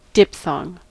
diphthong: Wikimedia Commons US English Pronunciations
En-us-diphthong.WAV